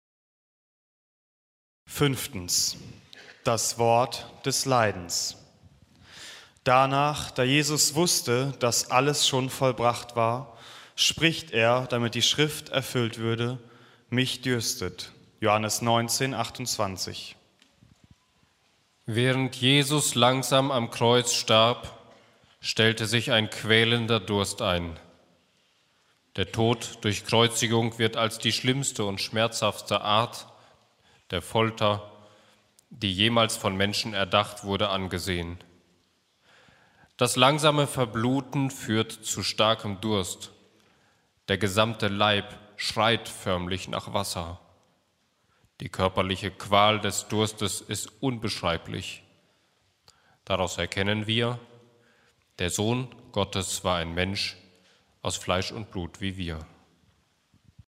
Passionssingen 2026
Lesung